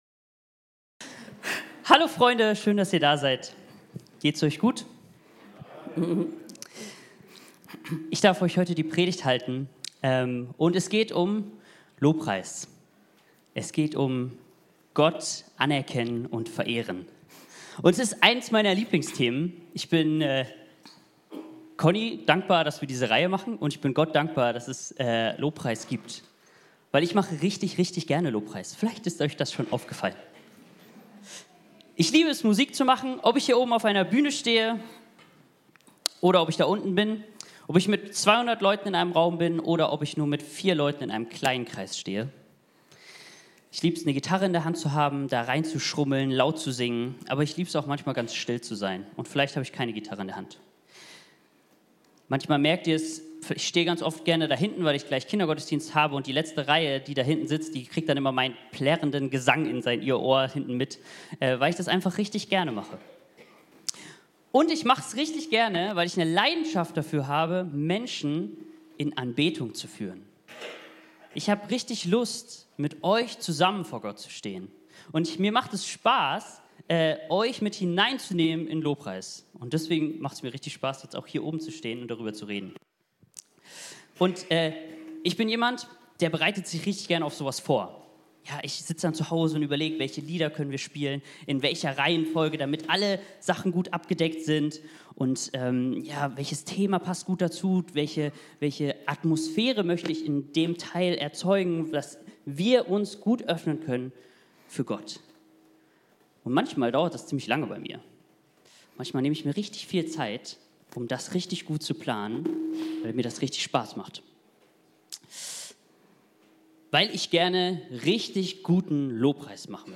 predigt_260308.mp3